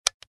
Звуки СМС
Тихий звук смс